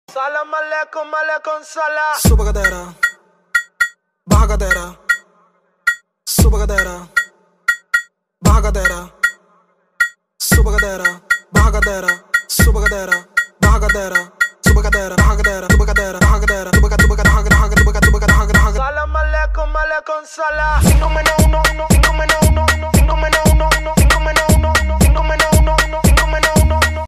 dembow